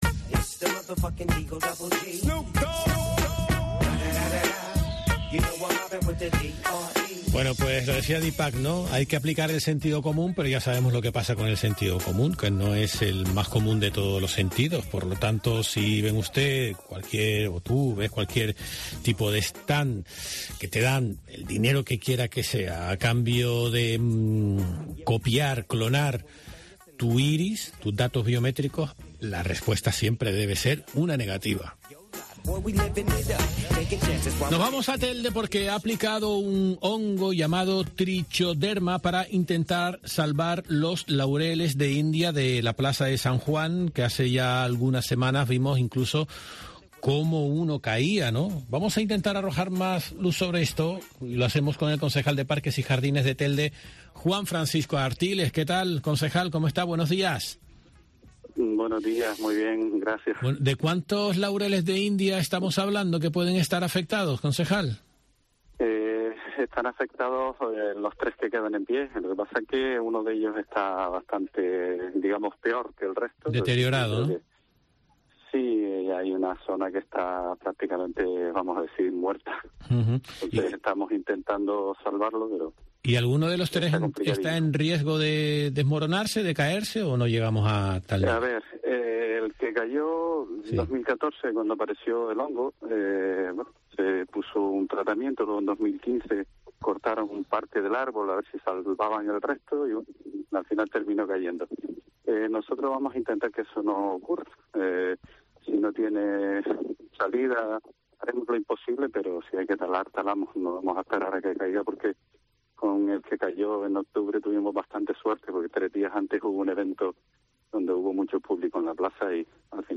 En declaraciones a Herrera en COPE Gran Canaria, Juan Francisco Artiles ha afirmado que esperarán una semana para comprobar si la fórmula propuesta para revertir la situación que pasa con aplicar el hongo 'Trichoderma' funciona, de no ser así, “no tendremos más remedio que aplicar el sentido común y protegiendo la seguridad de los ciudadanos, no nos quedará más remedio que talar el árbol”